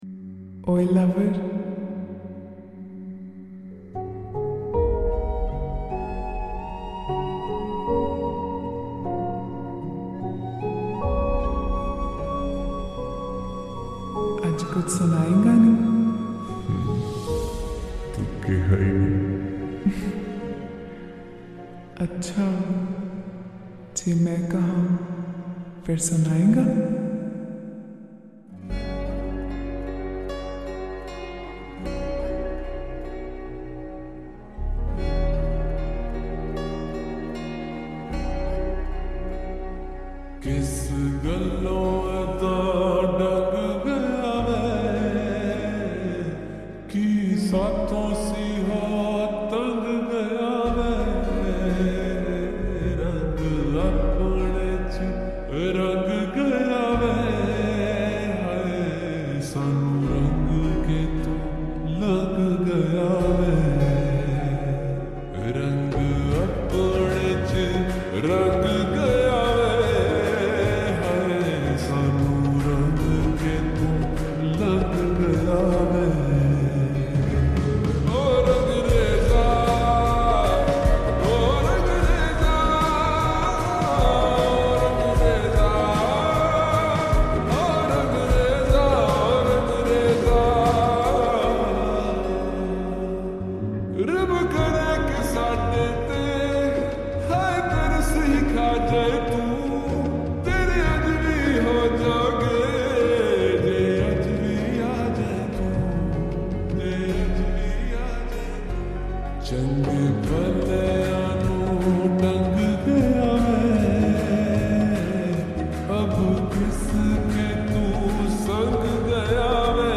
Slowed And Reverb
Sad song